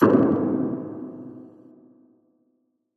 DDW4 PERC 2.wav